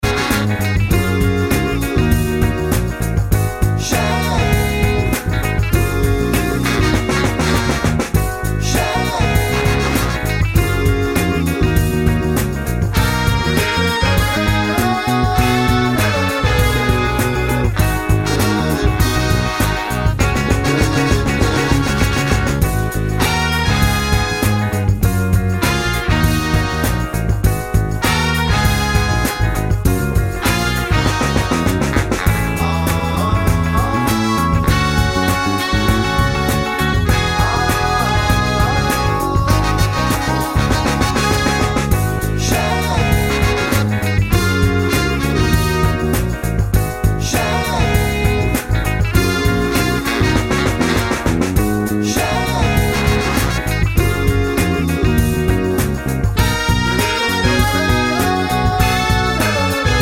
no Backing Vocals Soul